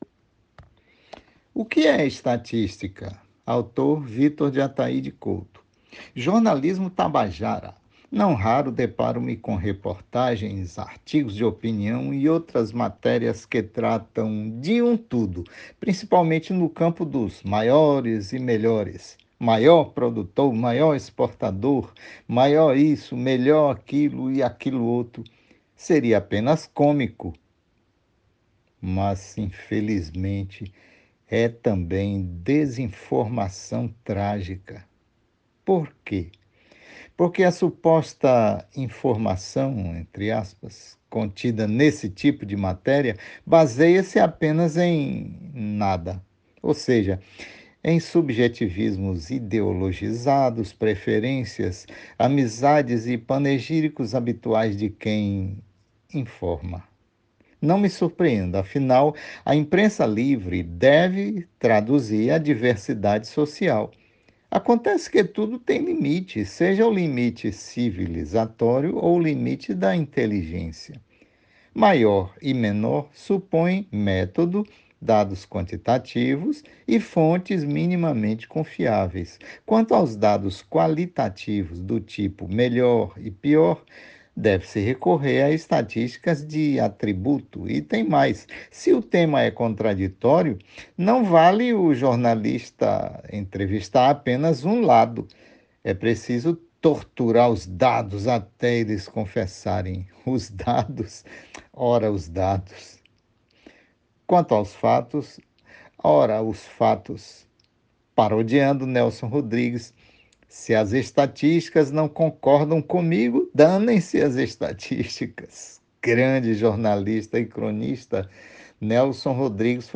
>> Ouça o texto narrado pelo autor: